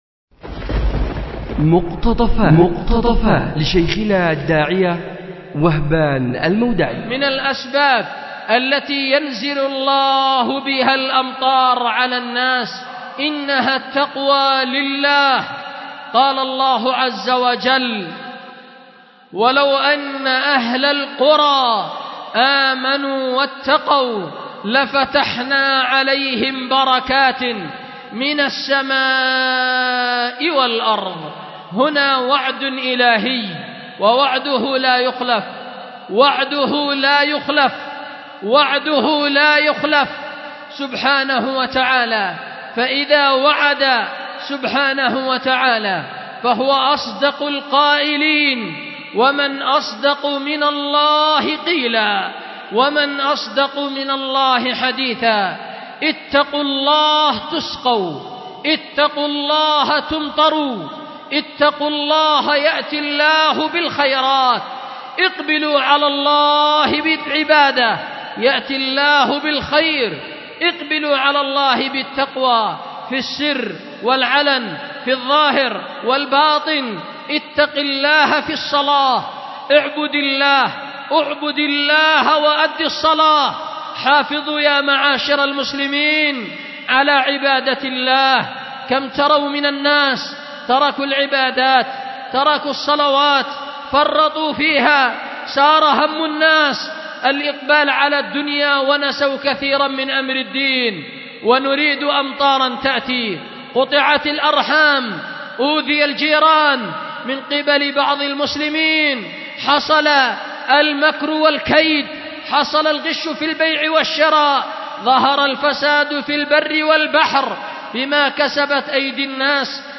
أُلقيت بدار الحديث للعلوم الشرعية بمسجد ذي النورين ـ اليمن ـ ذمار